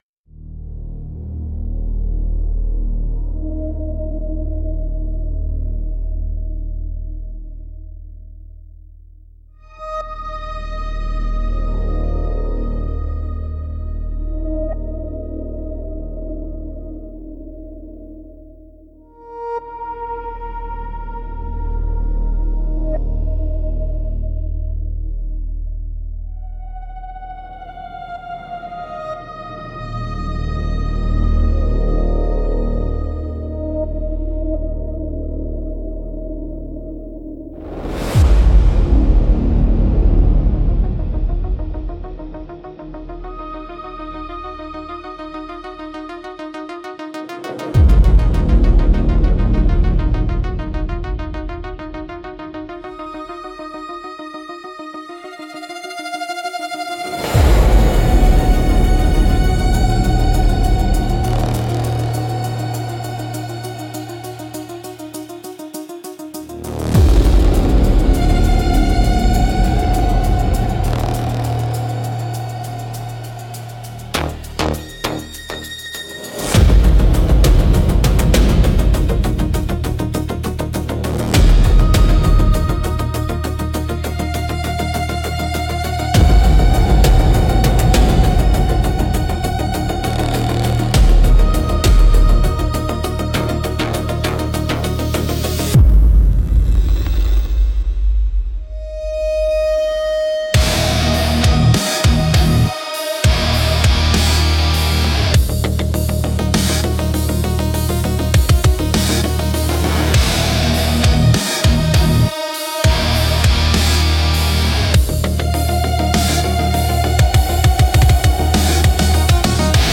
Instrumental - Ghost in the Machine Code 3.58